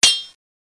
Sword1.mp3